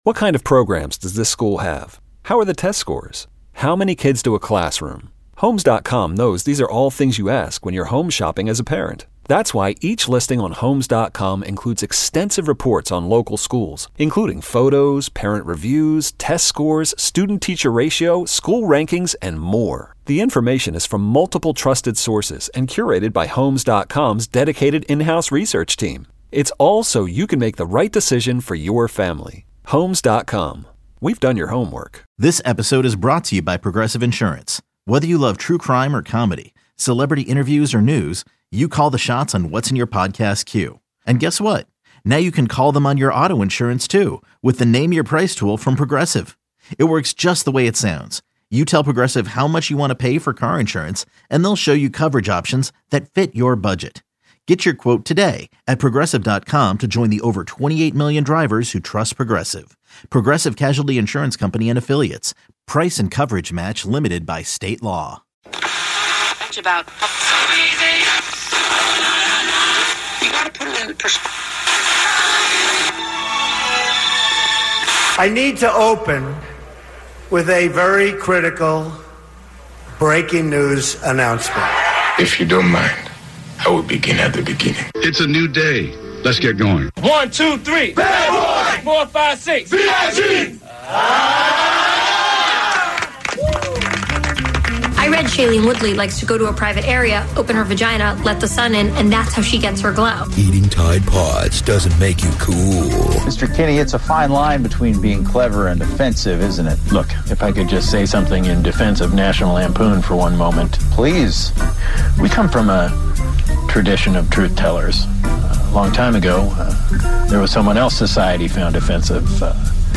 Natasha Leggero and Moshe Kasher In Studio.